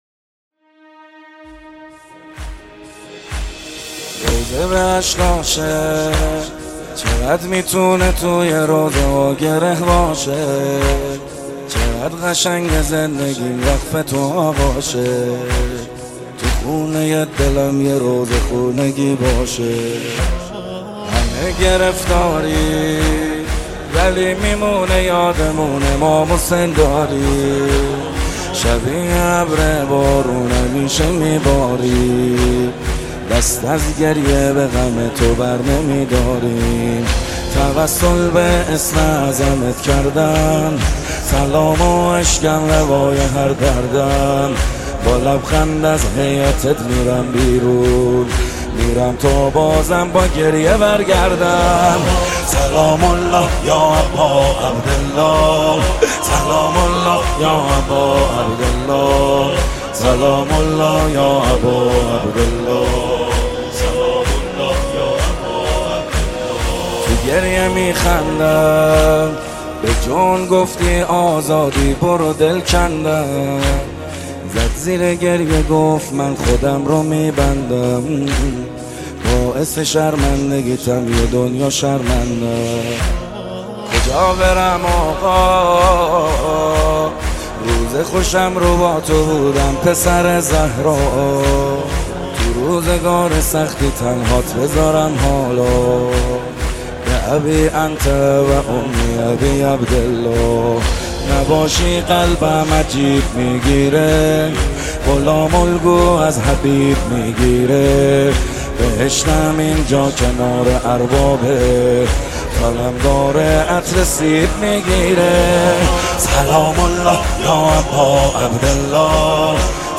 مذهبی
مداحی استودیویی